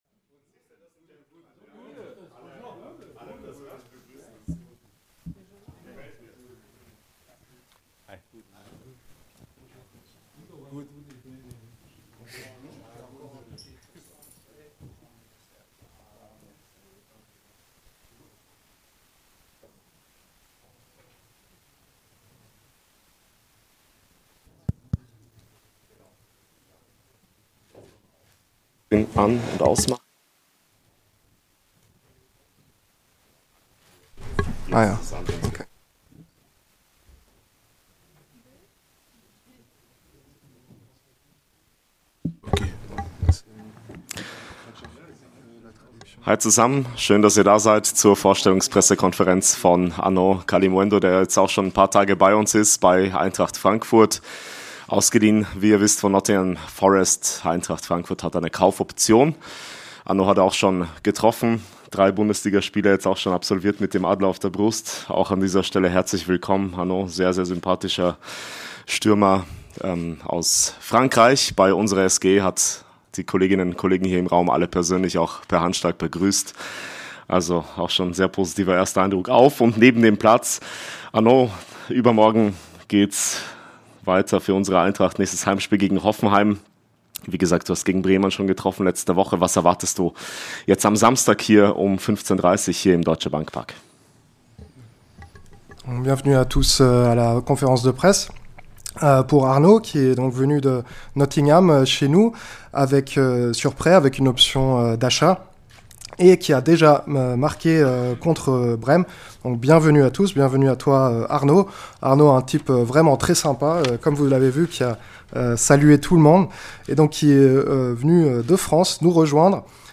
Neuzugang Arnaud Kalimuendo spricht auf der Pressekonferenz über seinen Wechsel zur Eintracht, die Stimmung in der Mannschaft und seine Ziele.